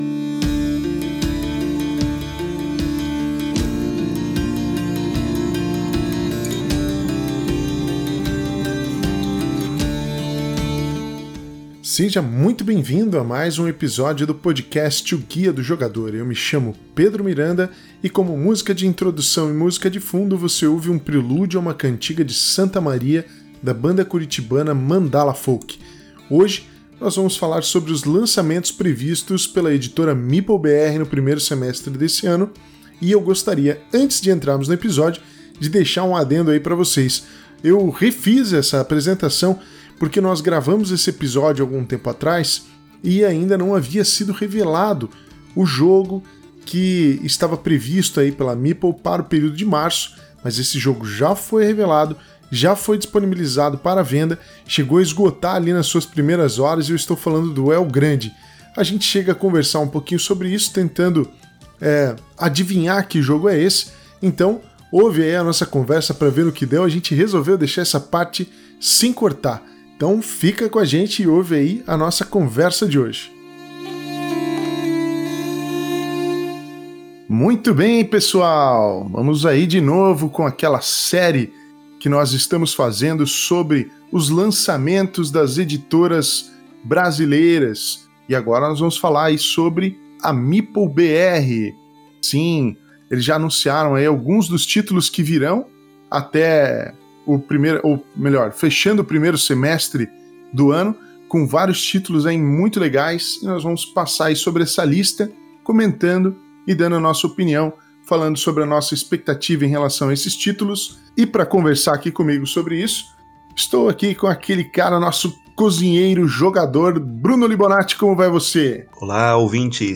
Mais um papo sobre os lançamentos de uma editora nacional. Dessa vez falamos do que vem pela Meeplebr falando das nossas expectativas e impressões.